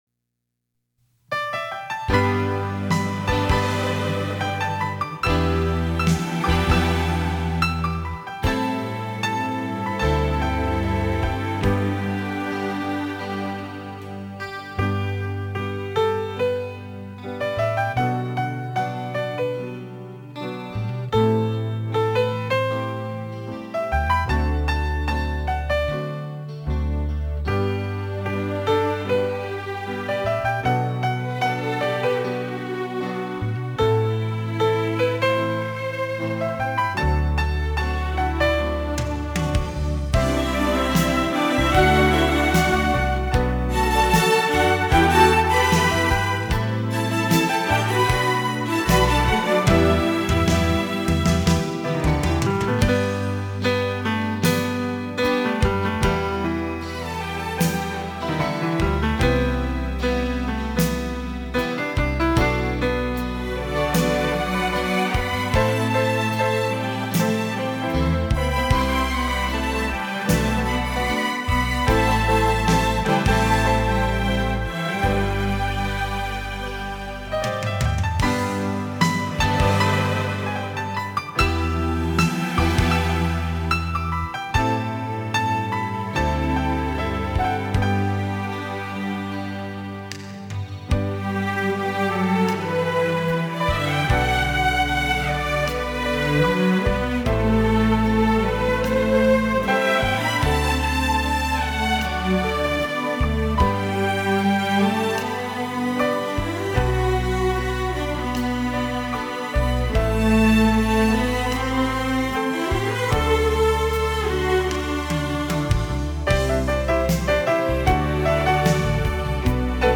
浪漫钢琴&浪漫小提琴
钢琴的琴键与小提琴的琴弦